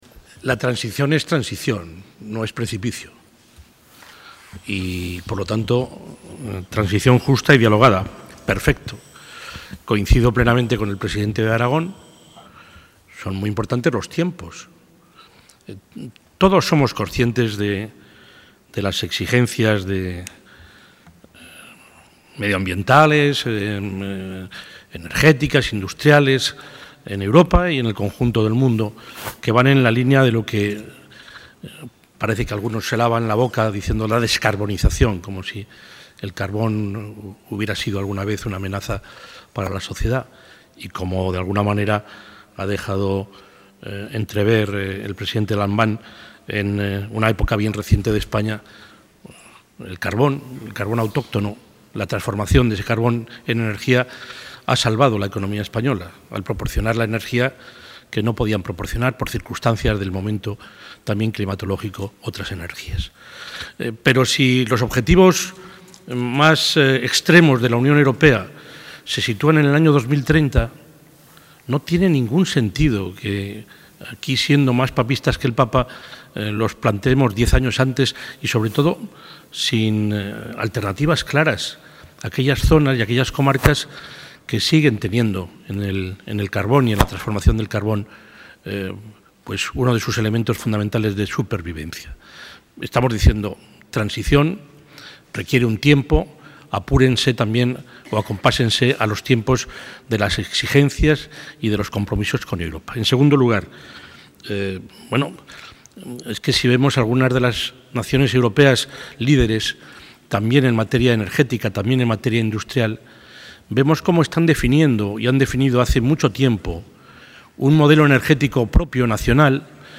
Intervención del presidente de la Junta de Castilla y León.